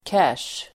Ladda ner uttalet
Uttal: [käsj:]